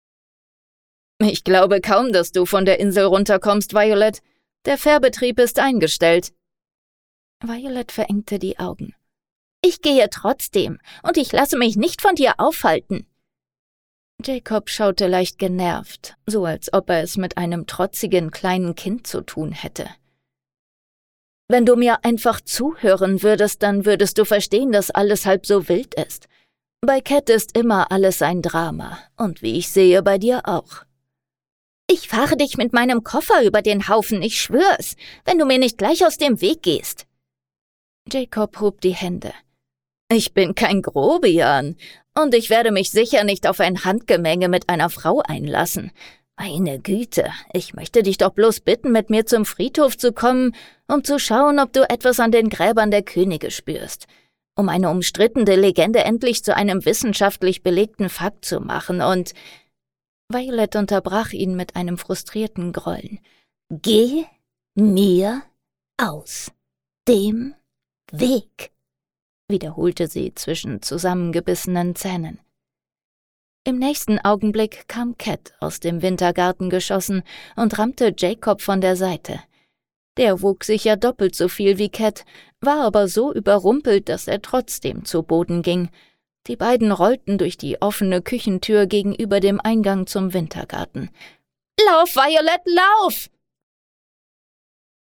Willkommen in der Hörbuch Welt!
Ein Hörbuch, das Nervenkitzel und Weihnachtsflair meisterhaft vereint – ideal für alle, die ihre Adventszeit lieber mit Gänsehaut als mit Glühwein verbringen.
Weihnachtsgrab-Hoerprobe.mp3